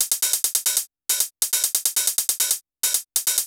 OP + CL HH-L.wav